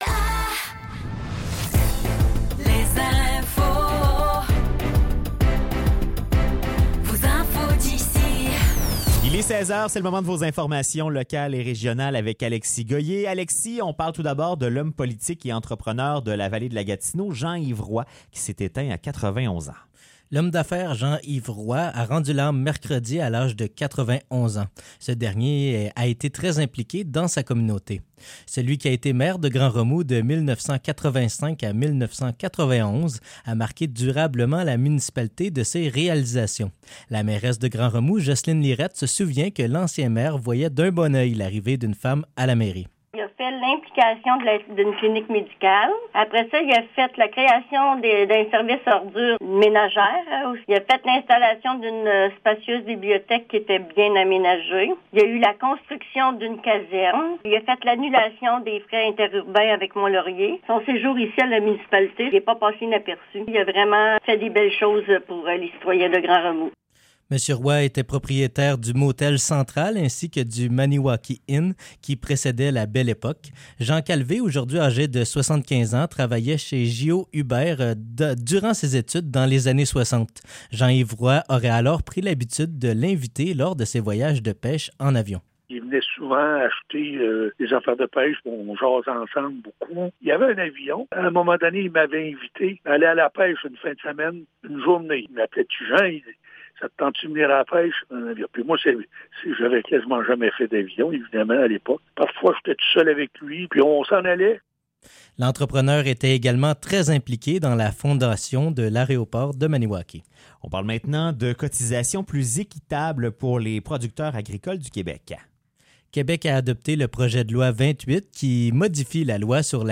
Nouvelles locales - 12 décembre 2023 - 16 h